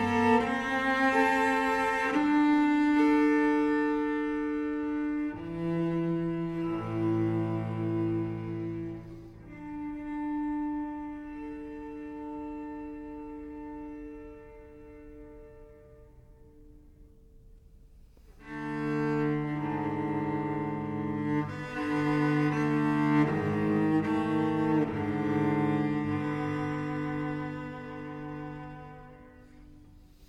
Musique audio